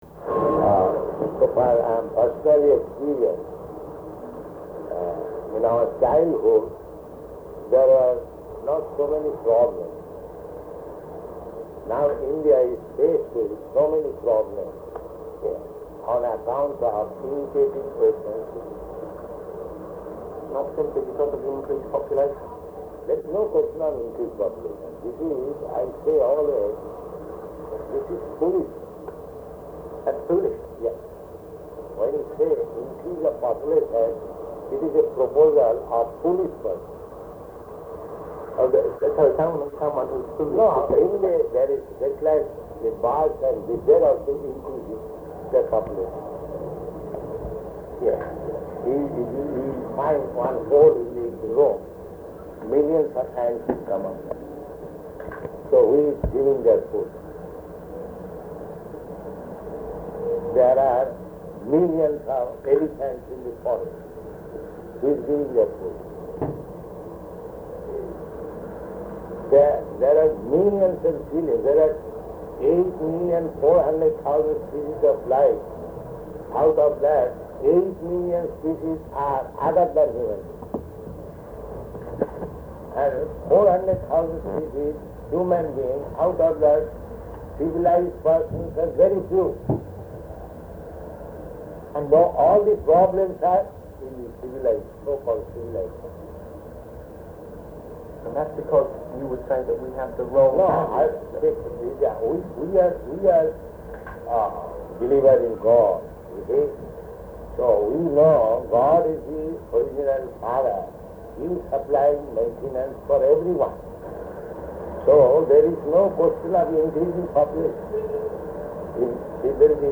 Interview with Journalists